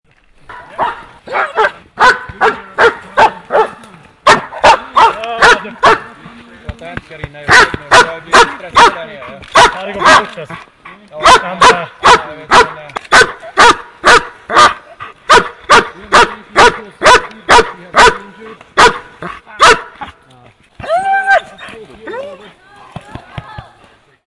Dog And People Sound Button - Free Download & Play